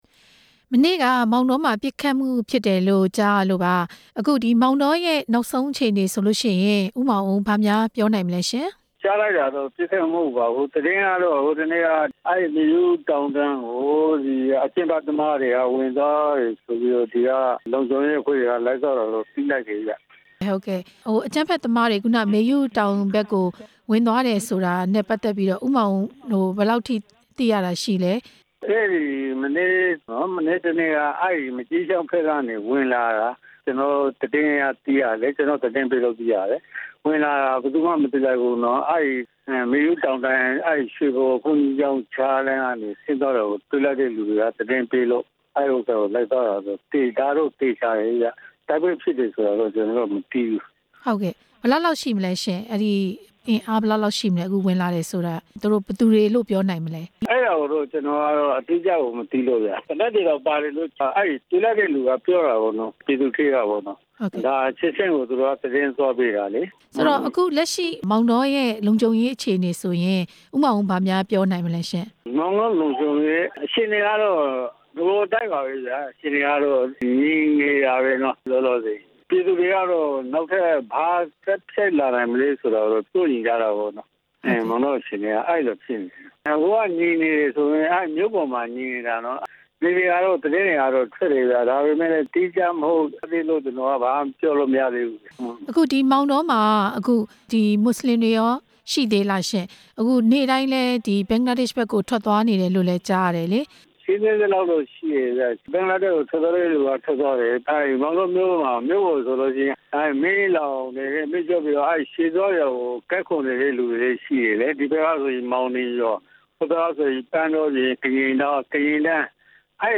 မောင်တောဒေသ လုံခြုံရေး လွှတ်တော်အမတ်နဲ့ မေးမြန်းချက်